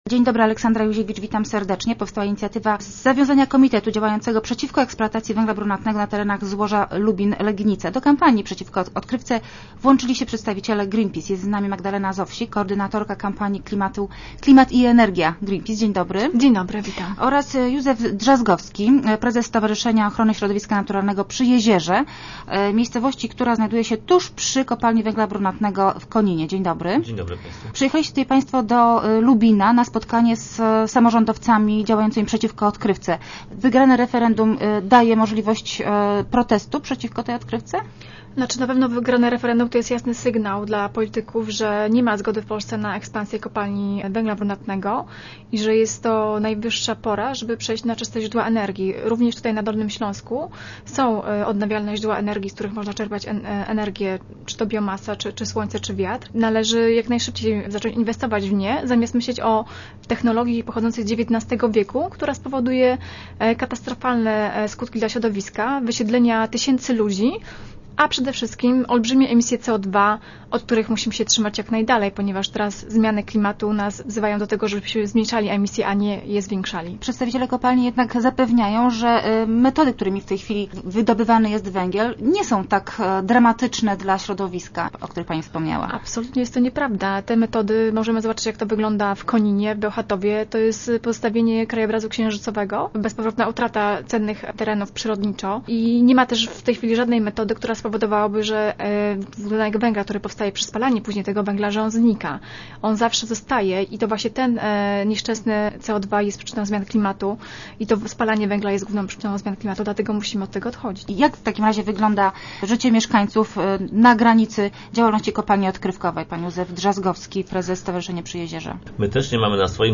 W Rozmowach Elki dzielą się swoimi doświadczeniami z działalności kopalni odkrywkowych.